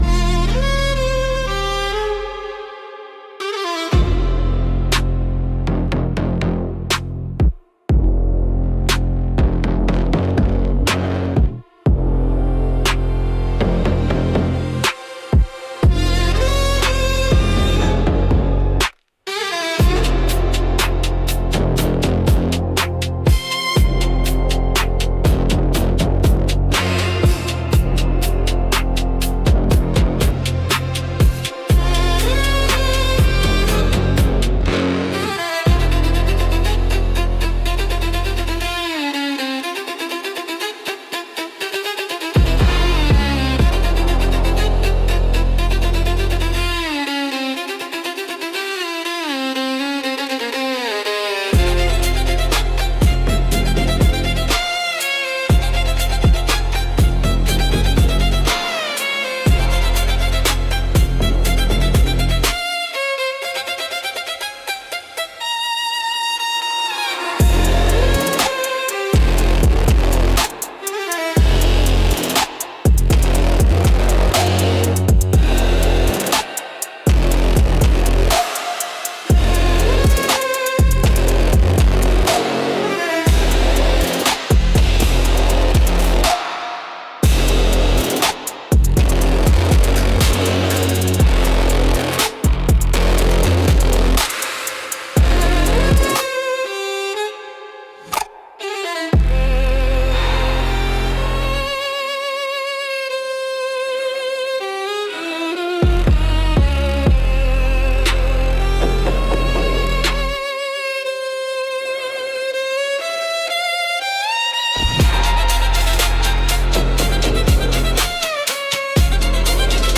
Instrumental - Heat Between the Lines